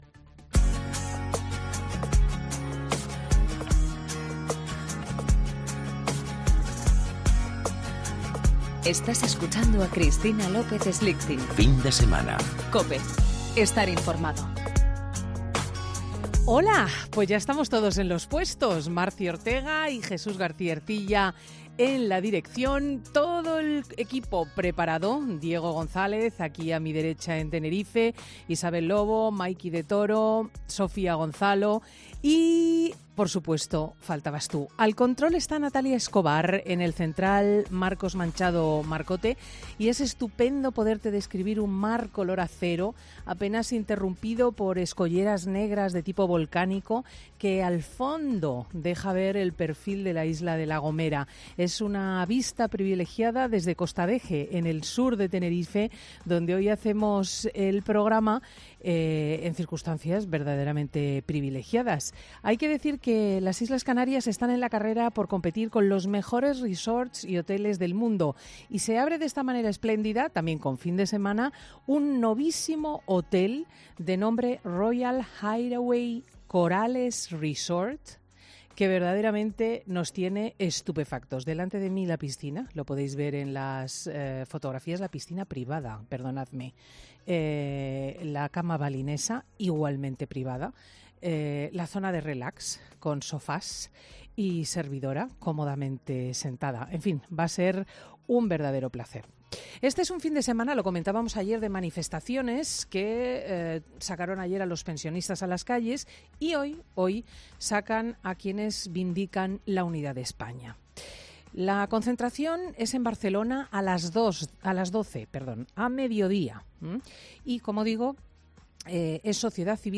Monólogo de Cristina López Schlichting
El editorial de Cristina López Schlichting, domingo 18 de marzo de 2018